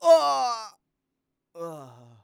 xys死亡3.wav 0:00.00 0:02.23 xys死亡3.wav WAV · 192 KB · 單聲道 (1ch) 下载文件 本站所有音效均采用 CC0 授权 ，可免费用于商业与个人项目，无需署名。
人声采集素材